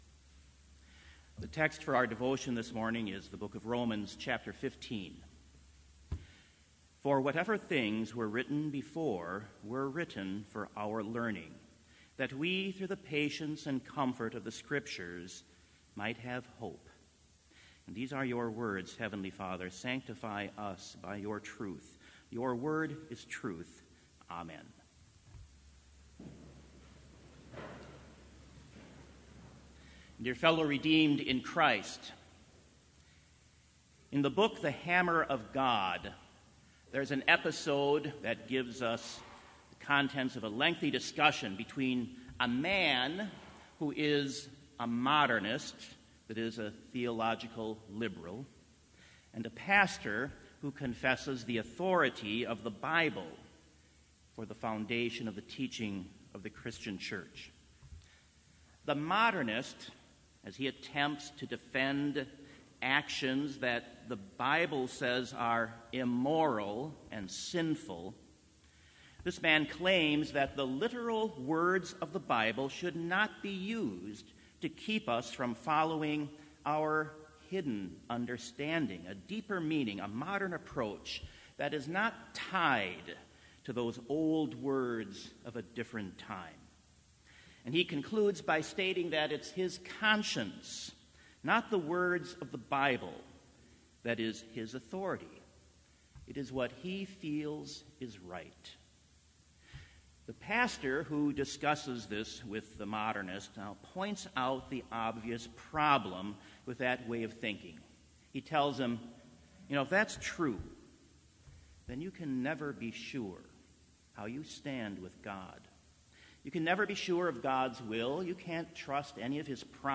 Complete Service
This Chapel Service was held in Trinity Chapel at Bethany Lutheran College on Wednesday, December 12, 2018, at 10 a.m. Page and hymn numbers are from the Evangelical Lutheran Hymnary.